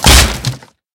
Minecraft Version Minecraft Version snapshot Latest Release | Latest Snapshot snapshot / assets / minecraft / sounds / mob / zombie / woodbreak.ogg Compare With Compare With Latest Release | Latest Snapshot
woodbreak.ogg